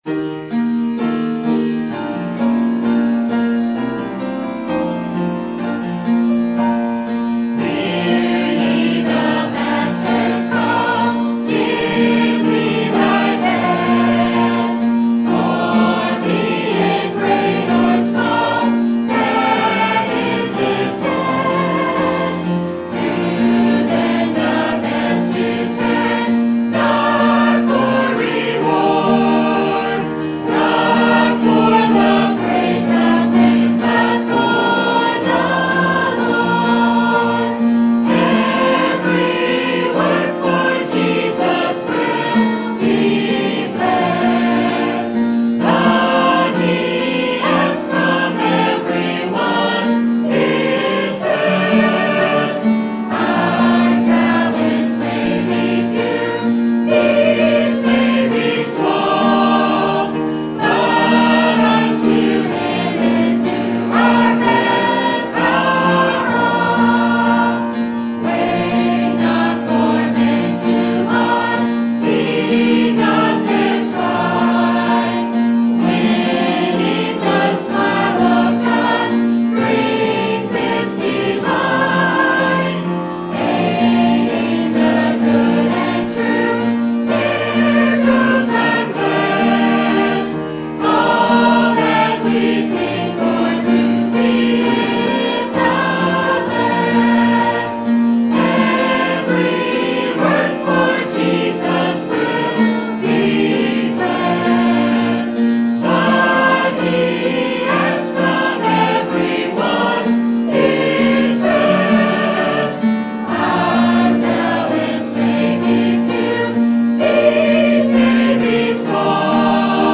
Sermon Topic: Missions Conference 2015 Sermon Type: Special Sermon Audio: Sermon download: Download (20.46 MB) Sermon Tags: Acts Missions Involved Contribution